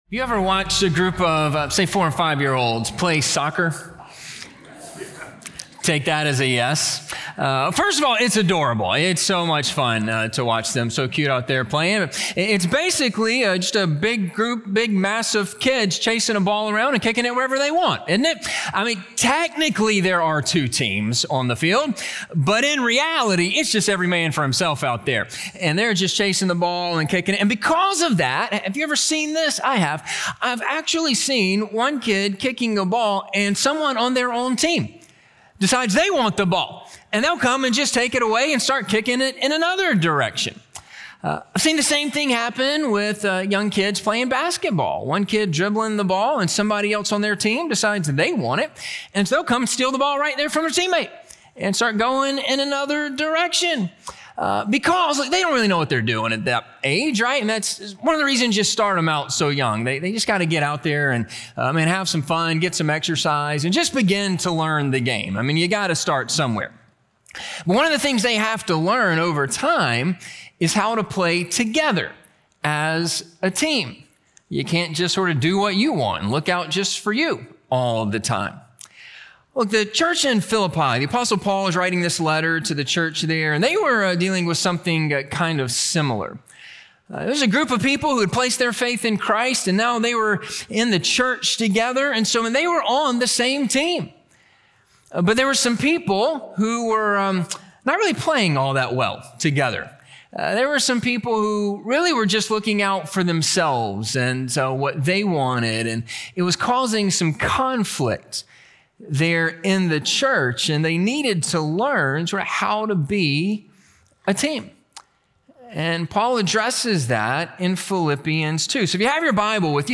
Have the Same Mindset as Christ - Sermon - Ingleside Baptist Church